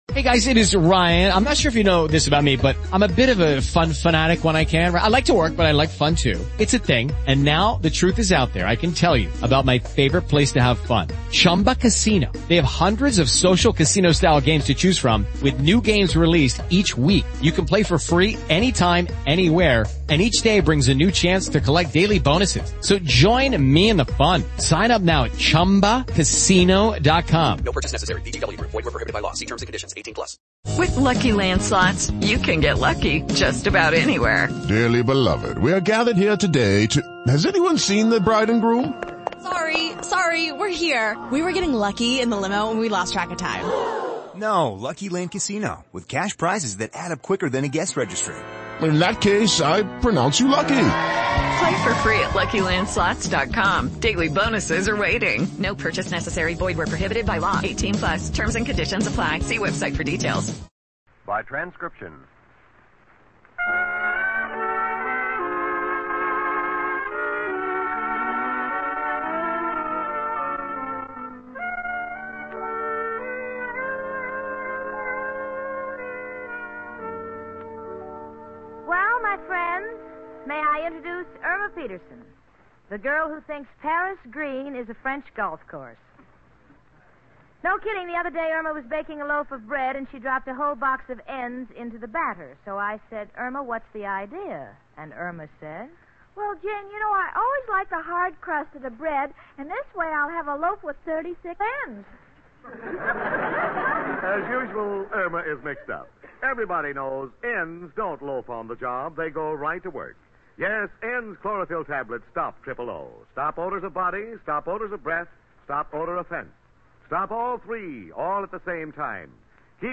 "My Friend Irma," the classic radio sitcom that had audiences cackling from 1946 to 1952! It was a delightful gem of a show, chronicling the misadventures of Irma Peterson, a ditzy yet endearing blonde, and her level-headed roommate Jane Stacy. Irma, played to perfection by the inimitable Marie Wilson, was the quintessential "dumb blonde."